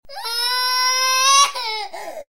cry1.ogg